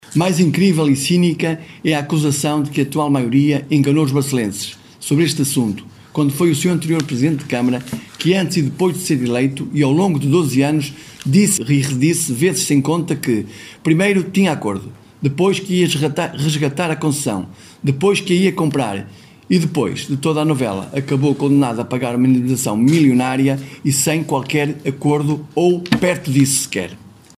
O presidente da Câmara teceu, ainda, duras criticas ao Partido Socialista de Barcelos, no seguimento da reunião de Câmara de ontem, e do comunicado socialista enviado às redacções. O autarca barcelense lembrou as promessas “do seu antecessor”.